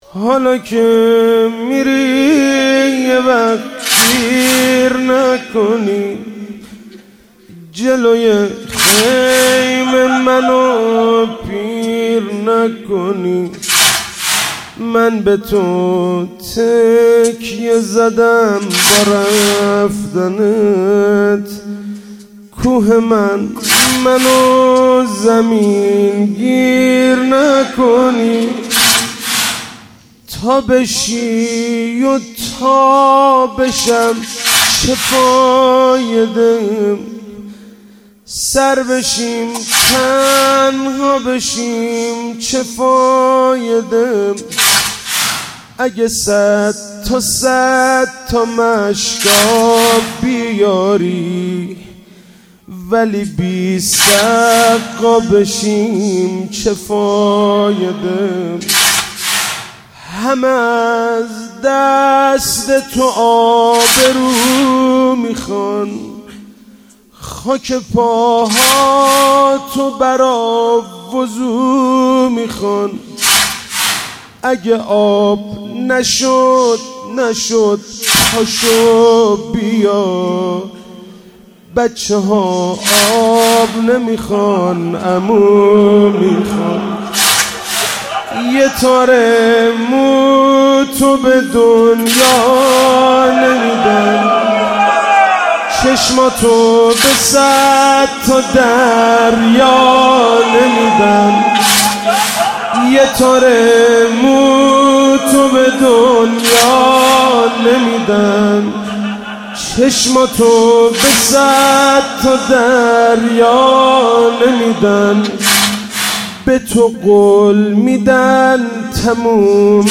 شب تاسوعا اینجوری واحد خوندن بی نظیره
هیئت انصارالحجه مشهد مقدس
مداحی